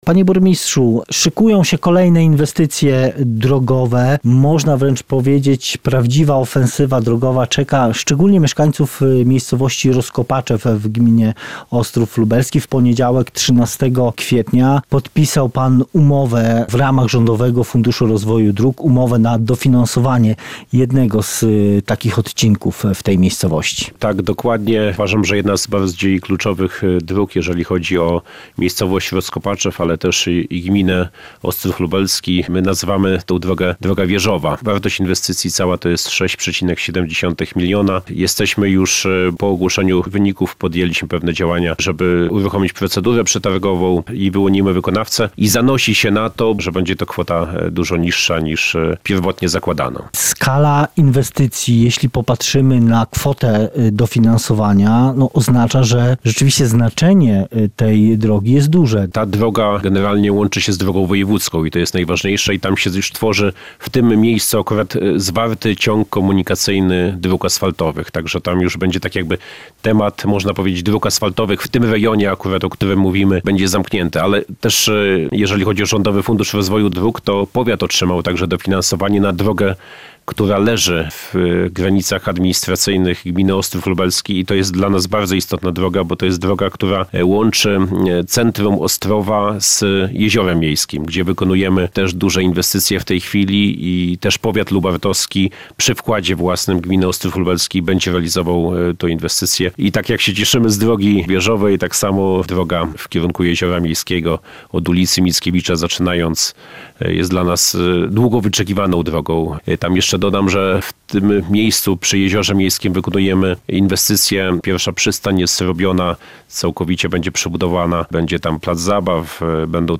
Włodzimierz Kołton, burmistrz Ostrowa Lubelskiego opowiada w rozmowie